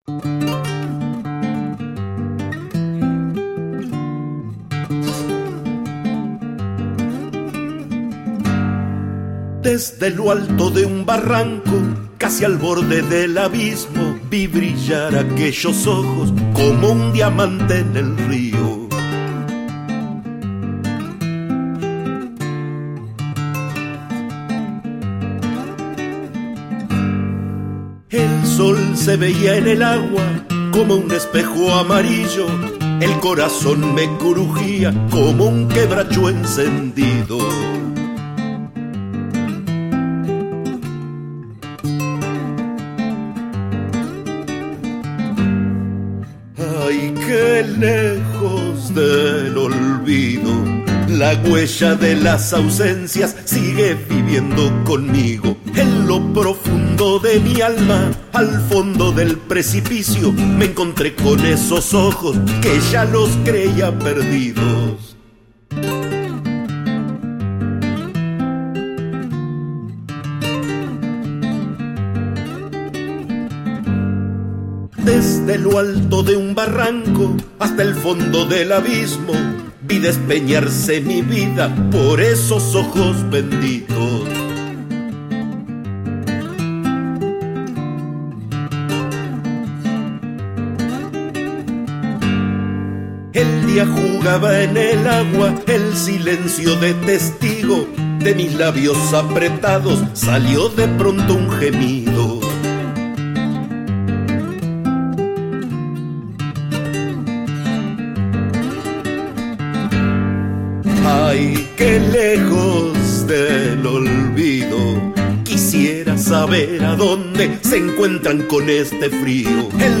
Chacarera